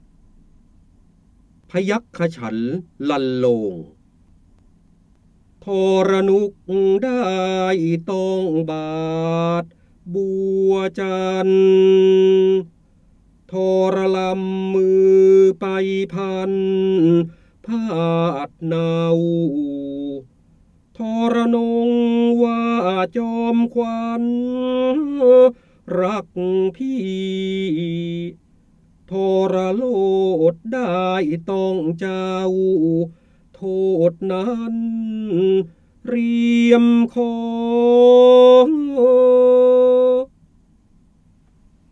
เสียงบรรยายจากหนังสือ จินดามณี (พระโหราธิบดี) พยัคฆฉันทลรรโลง
คำสำคัญ : การอ่านออกเสียง, ร้อยกรอง, ร้อยแก้ว, พระโหราธิบดี, พระเจ้าบรมโกศ, จินดามณี